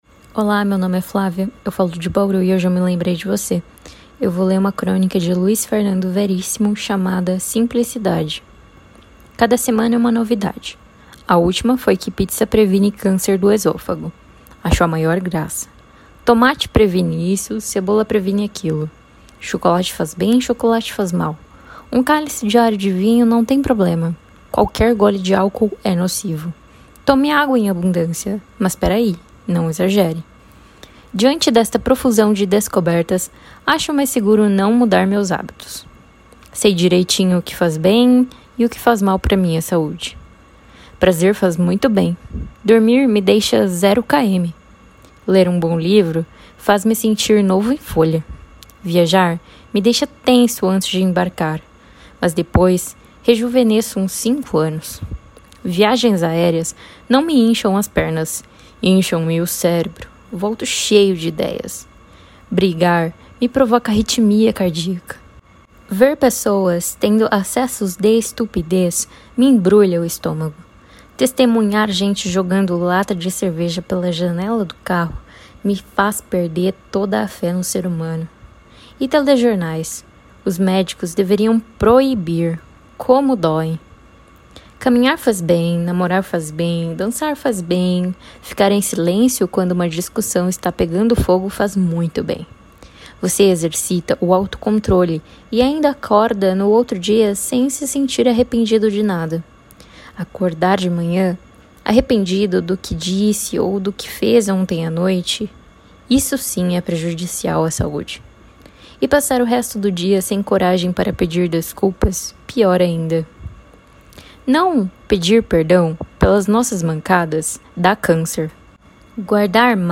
Crônica Português